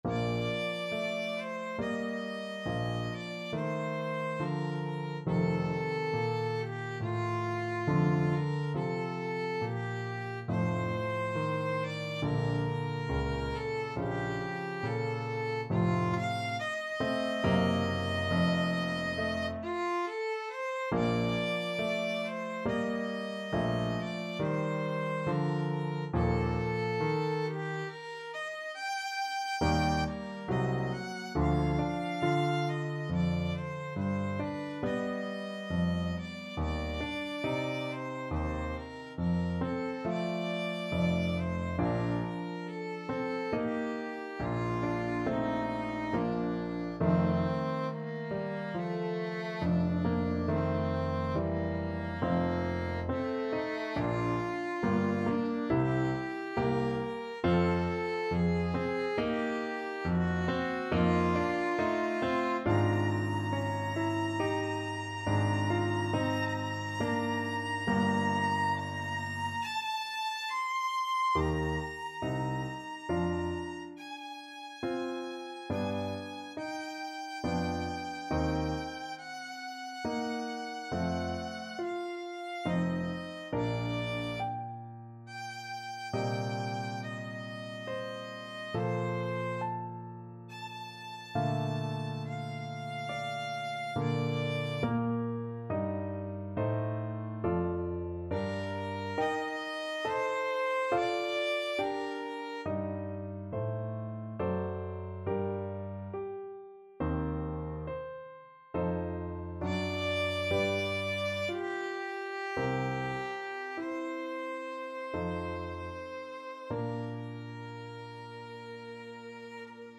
6/4 (View more 6/4 Music)
Andante =c.84 =69
Classical (View more Classical Violin Music)